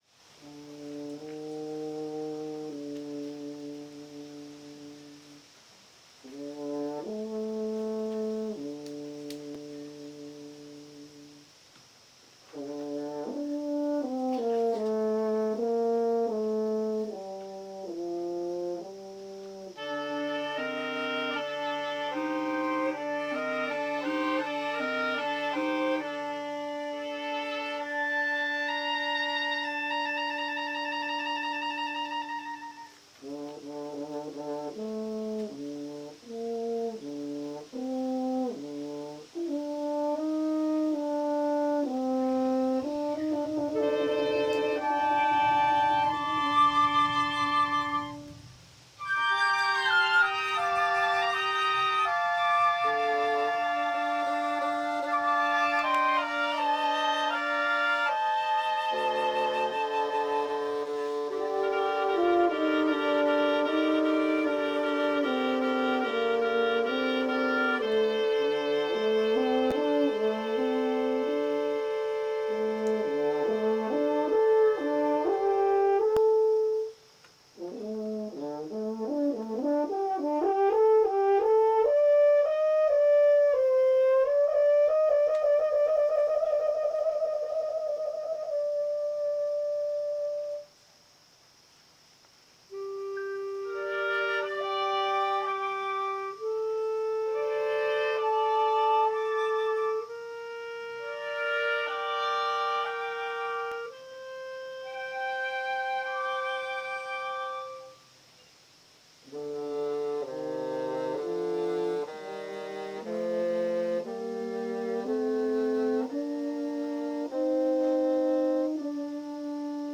for Woodwind Quintet (2021)